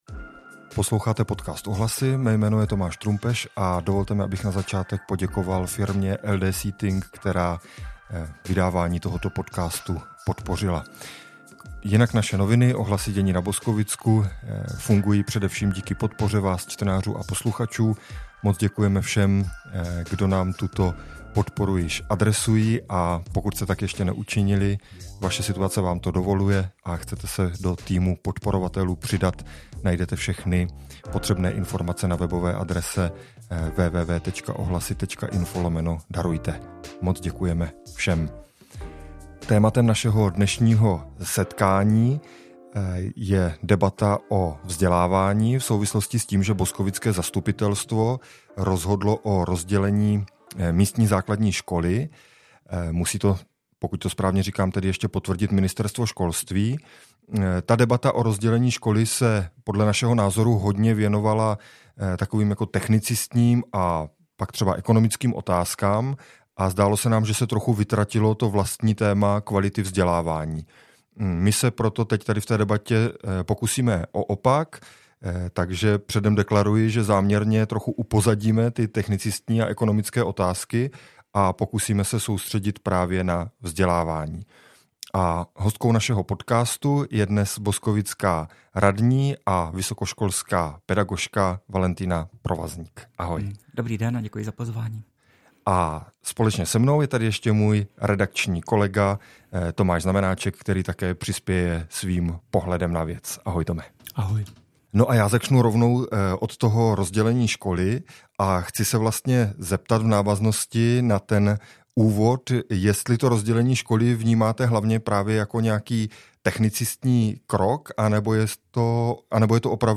Debata o vzdělávání s radní Valentýnou Provazník